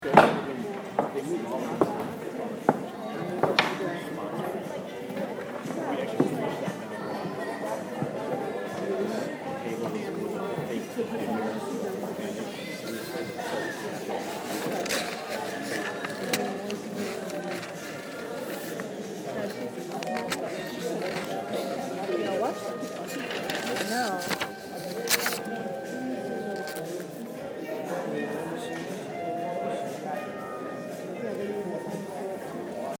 Location: NAB Light Booth, 7:20pm, 4/11/2013
The loneliness and lack of excitement of the light booth as we wait for rehearsal to start in contrast to the excitement of the vacuuming and last minute set touch-ups going on below us